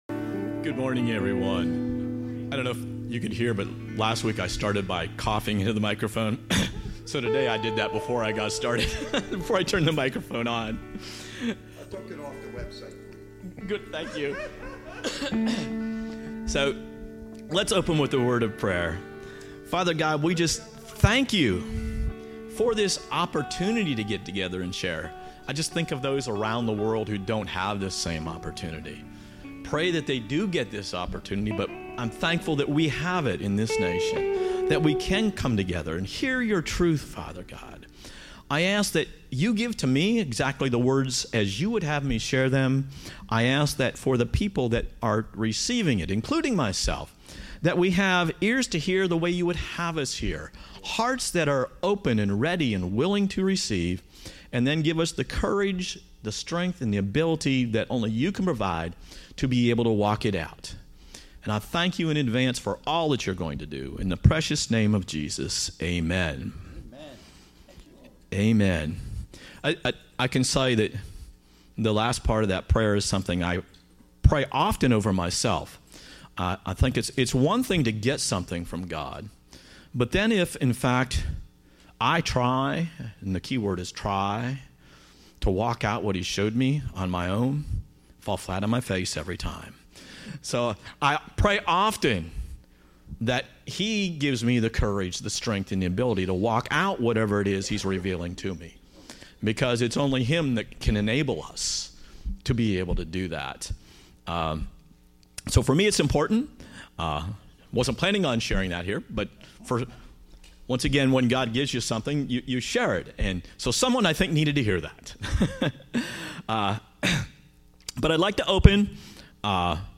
Teaching Service